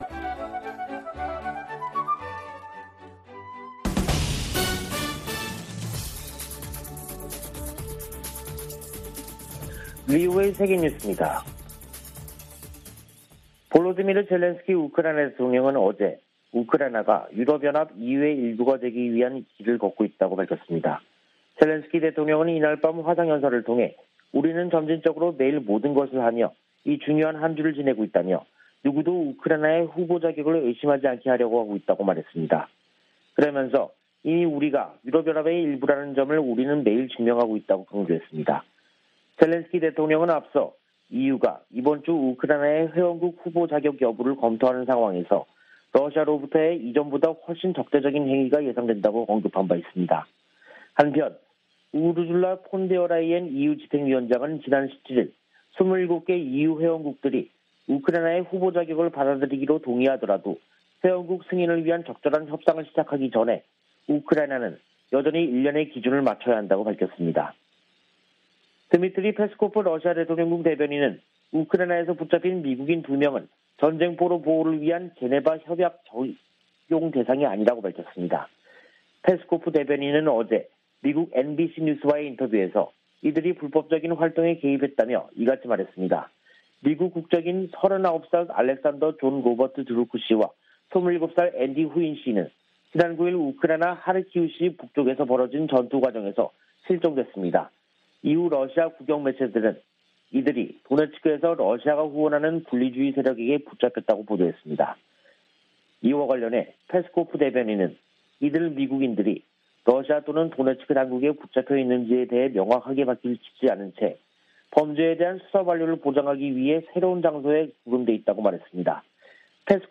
VOA 한국어 간판 뉴스 프로그램 '뉴스 투데이', 2022년 6월 21일 2부 방송입니다. 한국이 21일 자체 개발 위성을 쏘아올리는데 성공해, 세계 7번째 실용급 위성 발사국이 됐습니다. 북한이 풍계리 핵실험장 4번 갱도에서 핵실험을 하기 위해서는 수개월 걸릴 것이라고 미국 핵 전문가가 전망했습니다. 북한이 과거 기관총과 박격포 탄약, 수류탄 등 약 4천만개의 탄약을 중동 국가에 판매하려던 정황이 확인됐습니다.